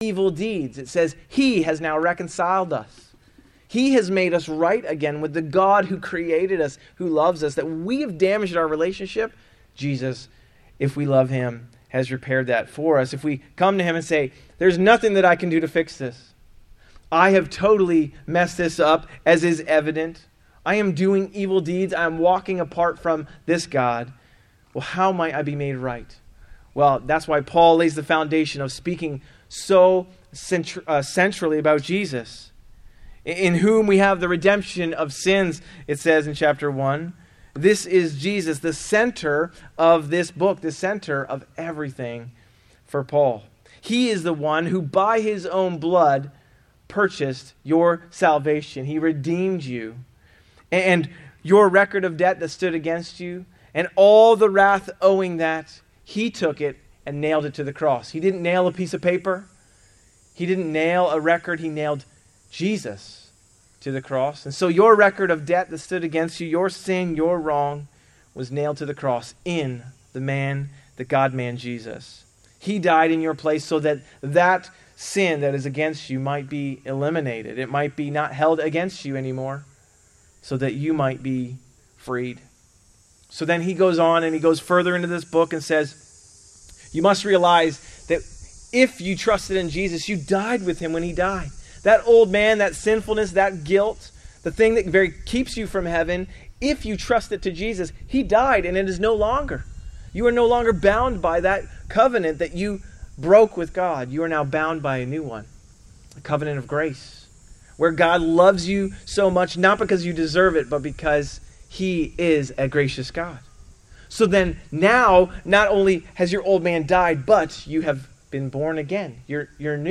A message from the series "In Christ Alone."